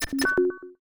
ScannerUse1.ogg